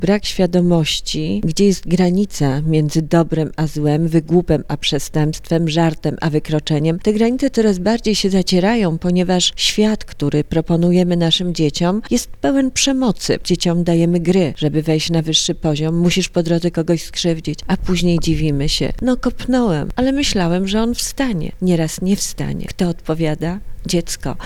Dziś (30.11.16), naszym Gościem Dnia na 90.3 FM była sędzia Anna Maria Wesołowska.